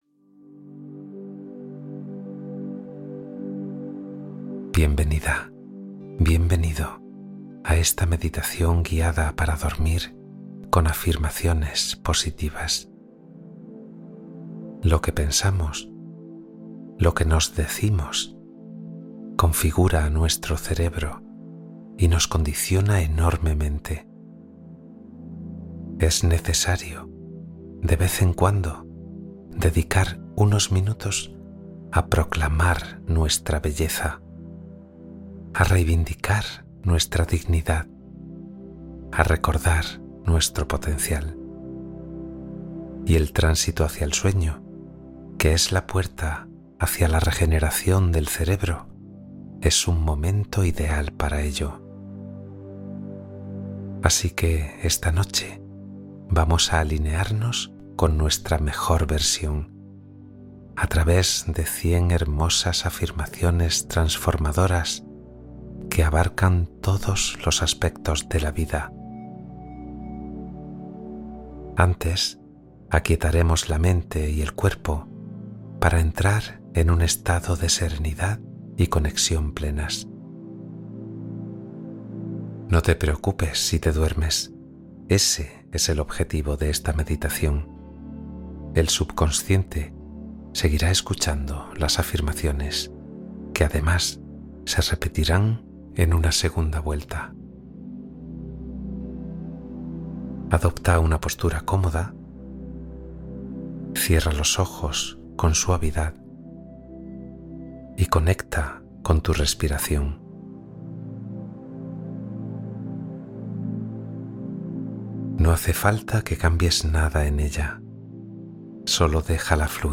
Meditación para dormir con afirmaciones "Yo Soy" y descanso reparador